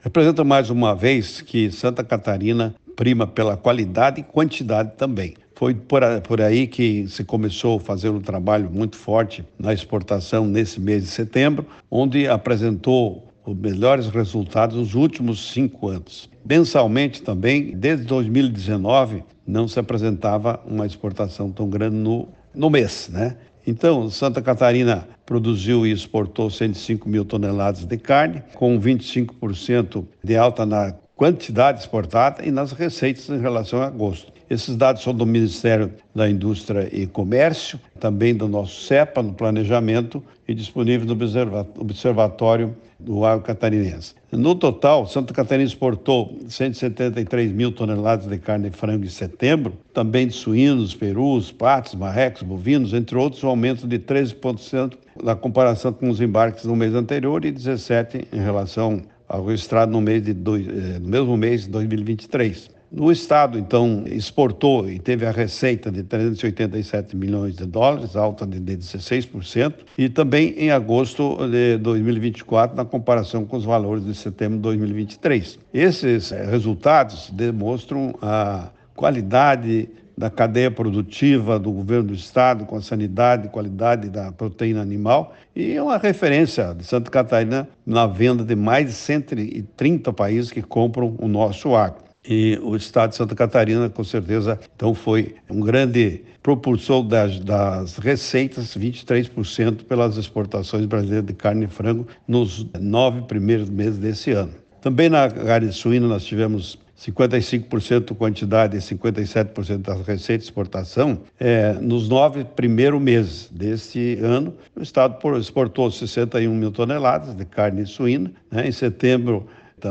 Para o secretário de Estado do Turismo, Evandro Neiva, os resultados são fruto de um trabalho integrado da gestão do Governo de Santa Catarina para atrair cada vez mais turista ao estado: